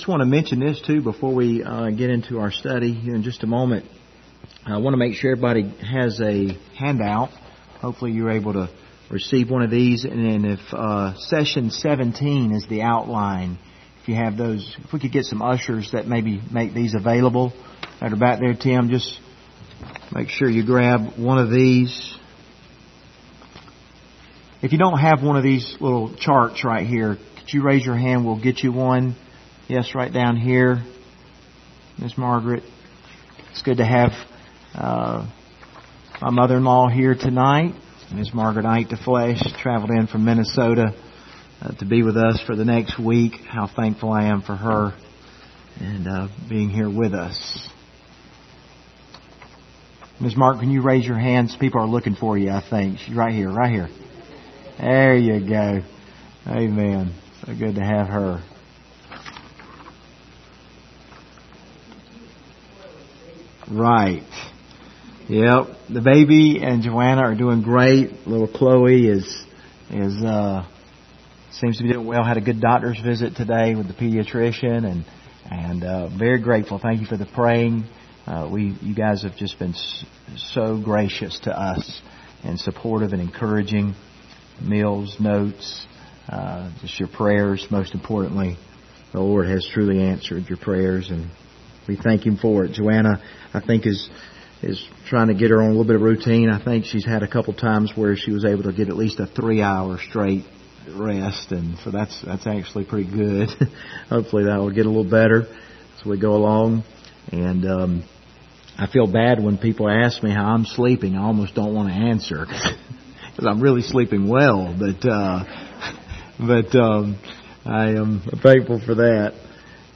Isaiah 41:10 Service Type: Wednesday Evening Topics: anxiety , fear